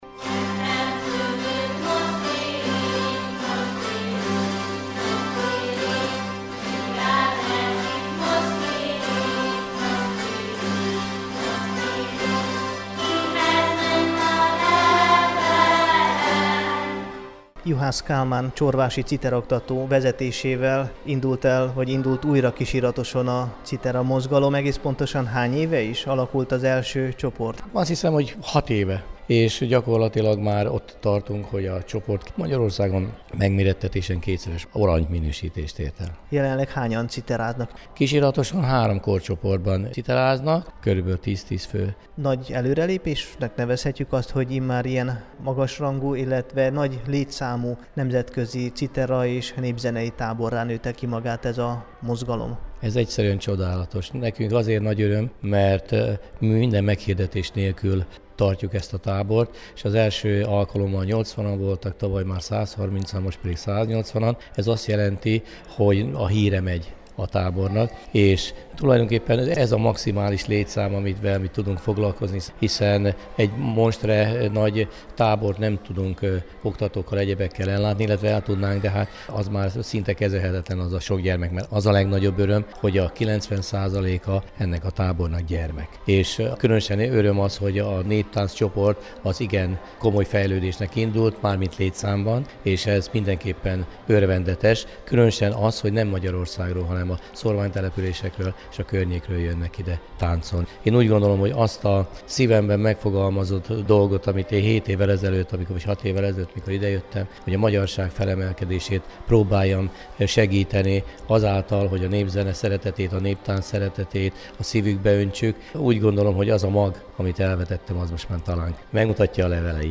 kisiratosi_citeratabor.mp3